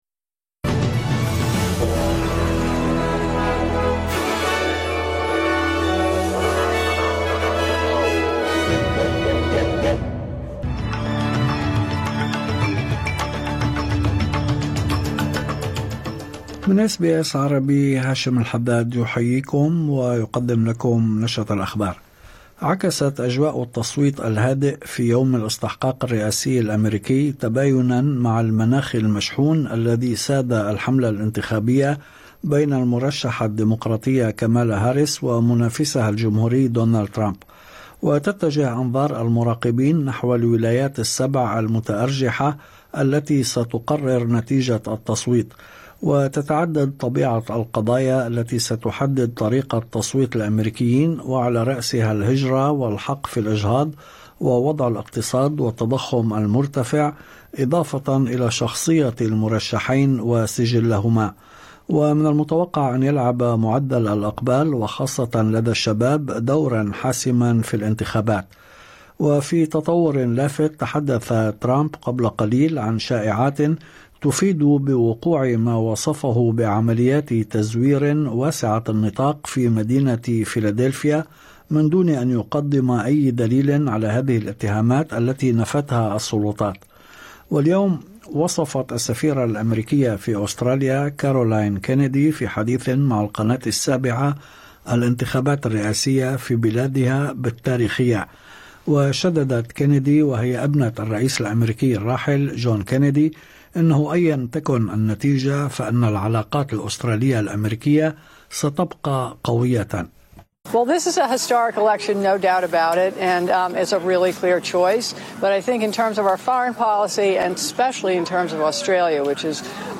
نشرة أخبار الظهيرة 6/11/2024